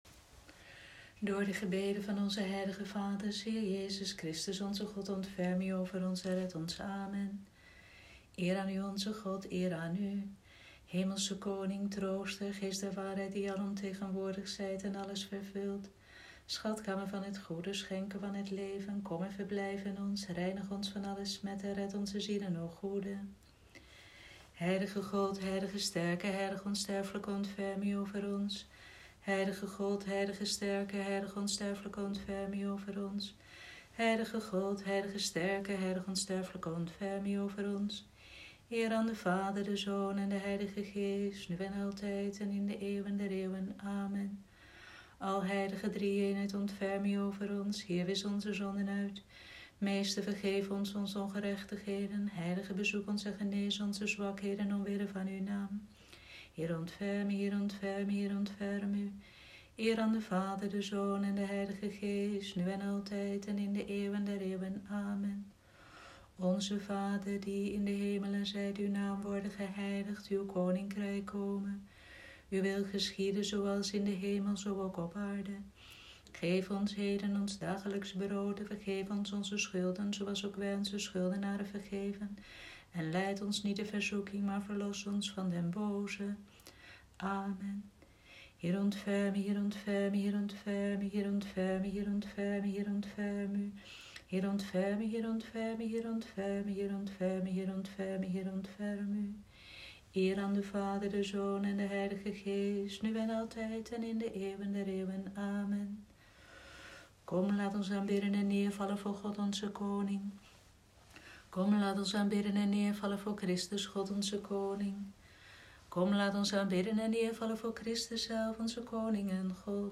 Vespers Vrijdagavond, 3 April 2020
Vespers-vrijdagavond-3-april.m4a